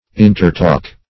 Intertalk \In`ter*talk"\, v. i.